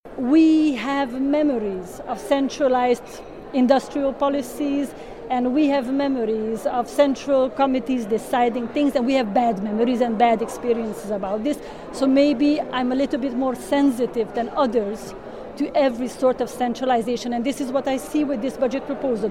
– To pomysły jak z krajów za komunistyczną „żelazną kurtyną” – powiedziała dziś przewodnicząca Komitetu, Węgierka Kata Tutto.